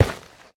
Minecraft Version Minecraft Version snapshot Latest Release | Latest Snapshot snapshot / assets / minecraft / sounds / block / nylium / step5.ogg Compare With Compare With Latest Release | Latest Snapshot
step5.ogg